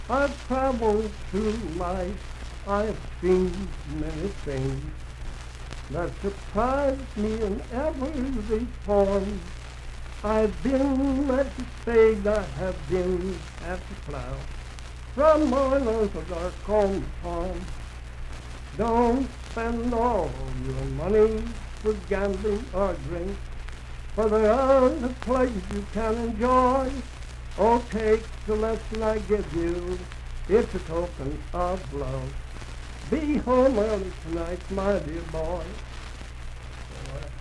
Unaccompanied vocal music
Verse-refrain 1(8).
Voice (sung)
Grant County (W. Va.)